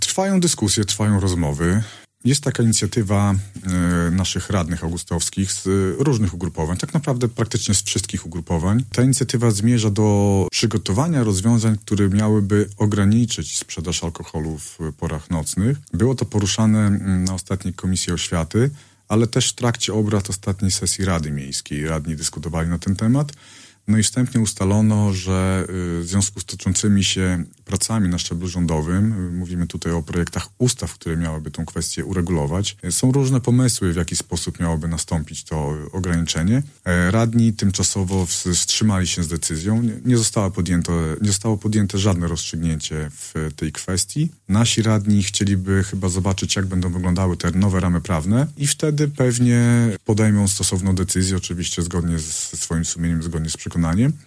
O szczegółach mówił we wtorek (04.11) w Radiu 5 Sławomir Sieczkowski, zastępca burmistrza Augustowa.